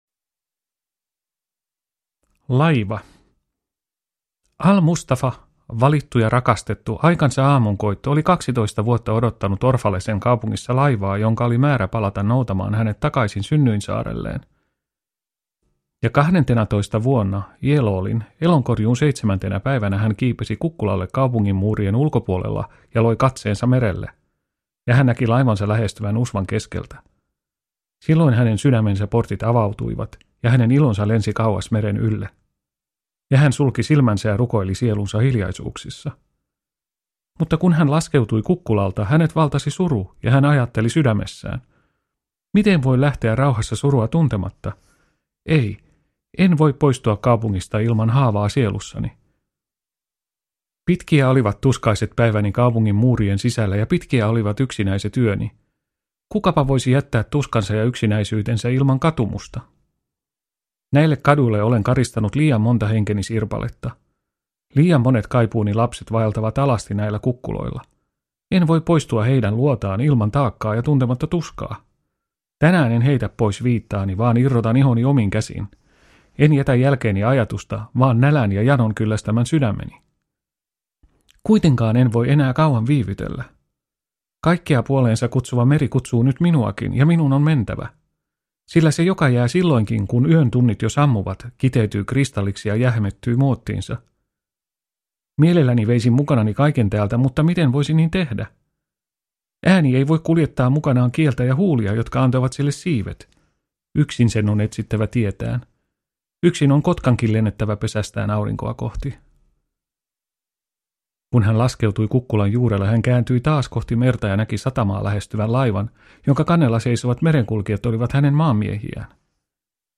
Profeetta – Ljudbok